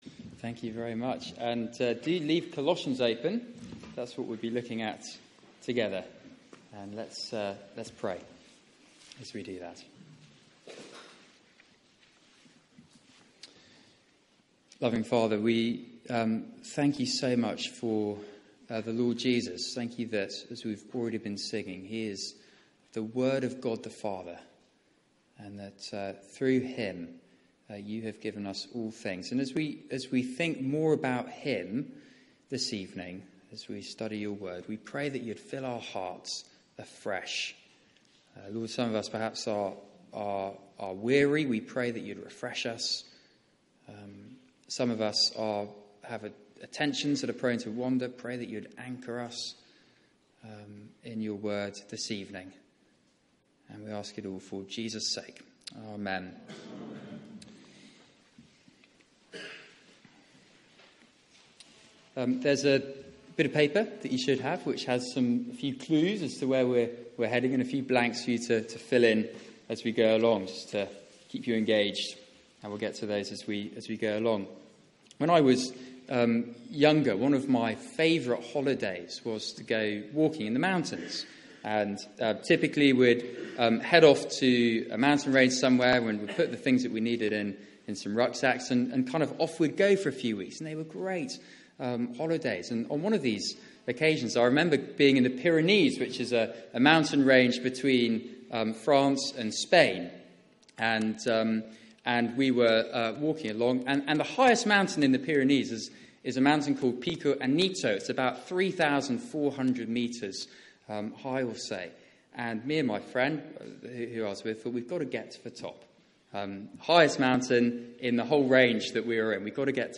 Media for 6:30pm Service on Sun 09th Oct 2016 18:30 Speaker
Series: Rooted in Christ Theme: Christ supreme over all Sermon